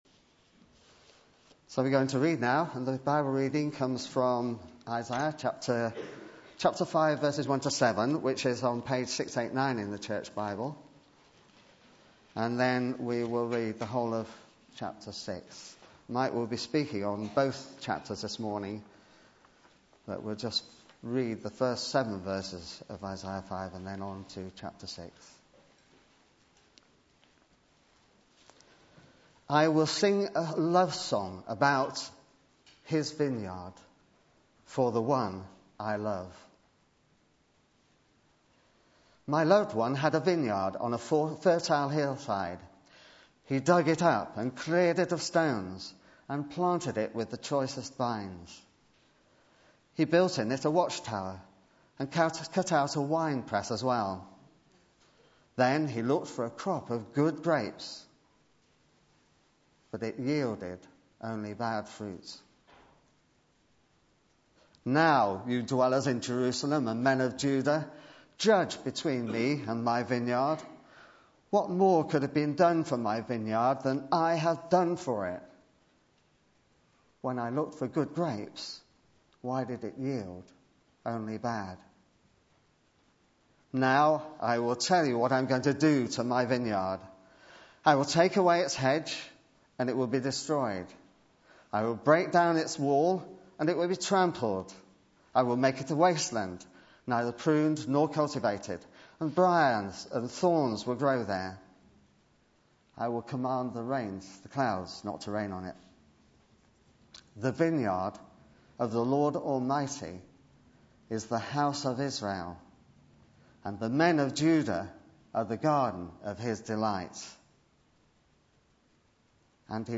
Media for Sunday Service on Sun 16th Sep 2012 11:00
Passage: Isaiah 5-6 Series: The Book of Isaiah Theme: Sermon